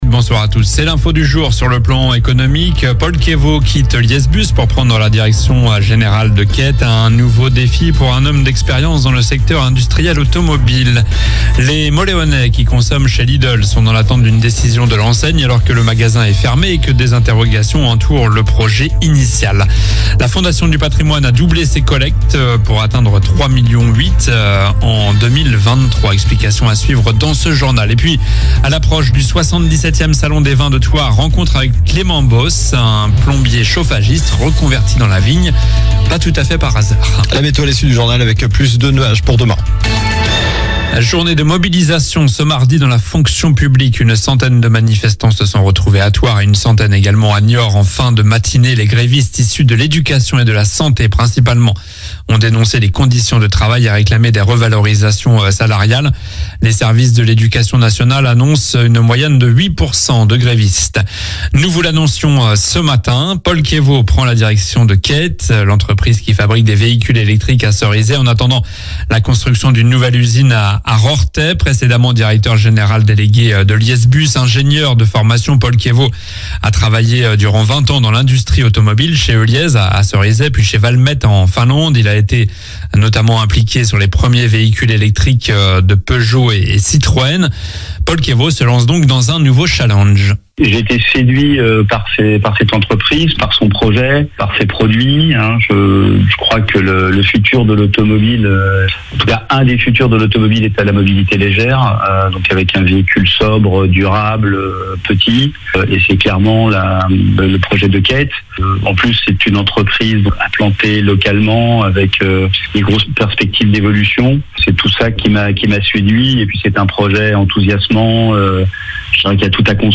Journal du mardi 19 mars (soir)